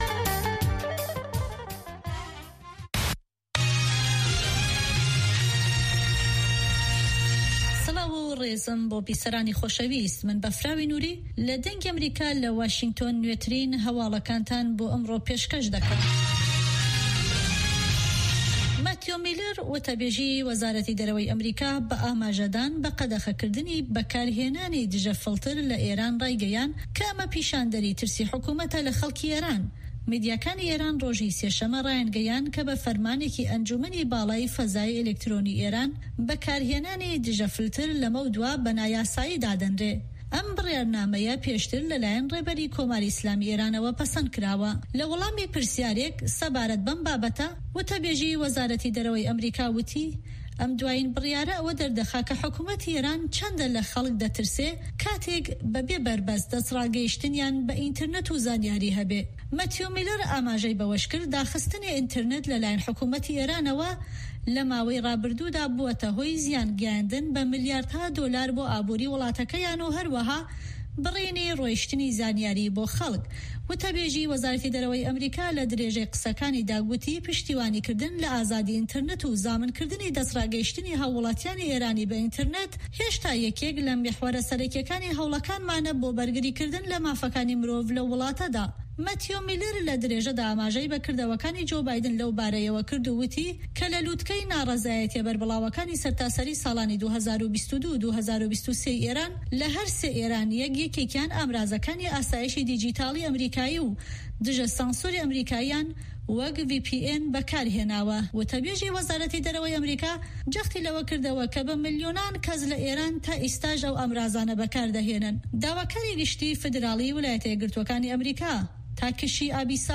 Nûçeyên 3’yê paşnîvro